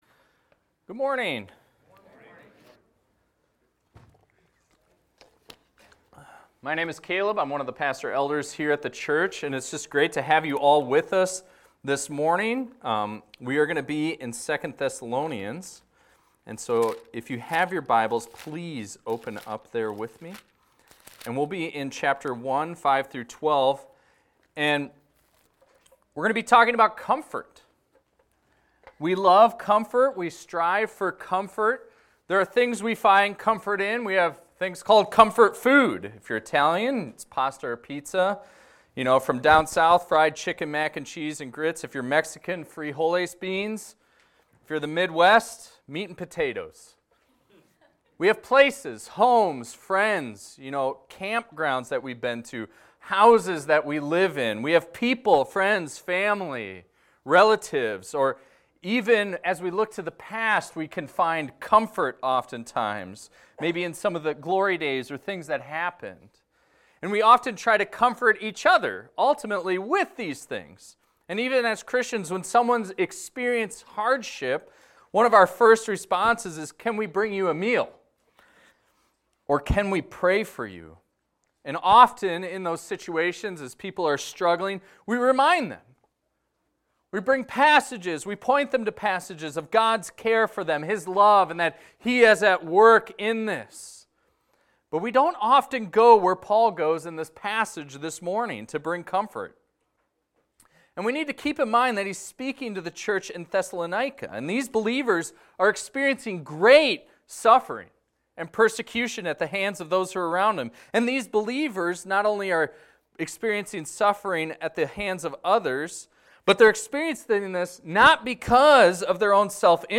This is a recording of a sermon titled, "God's Perfect Justice."